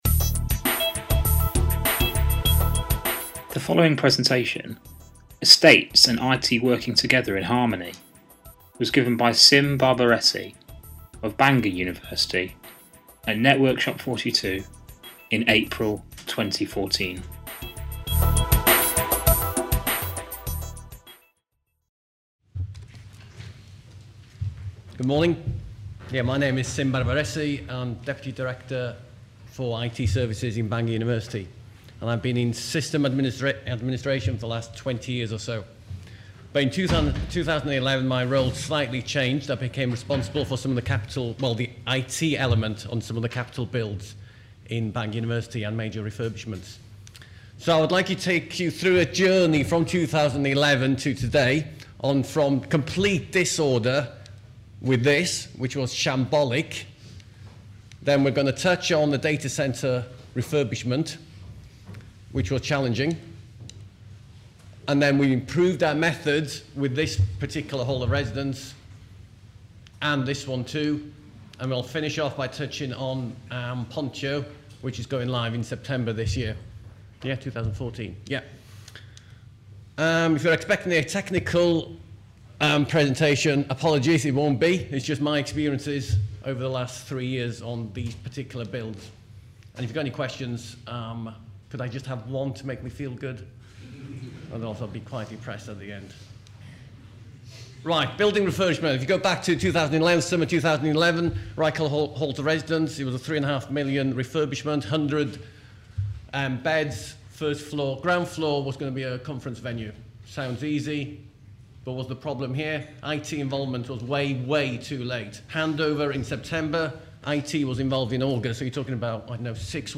Networkshop 42
If this sounds familiar then this talk may be of interest and relevance because, with the help of real-life examples, it details how Bangor University went from a chaotic unstructured and mixed environment to a standard installation model, this includes data rooms, wifi, open access rooms, CCTV, BMS, fibre, copper, etc. T